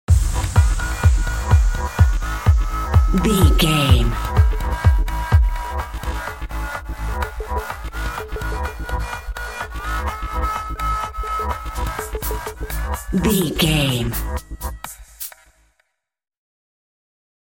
Top 40 Electronic Dance Music Stinger.
Aeolian/Minor
G#
Fast
energetic
uplifting
hypnotic
groovy
drum machine
synthesiser
house
techno
trance
synth leads
synth bass
uptempo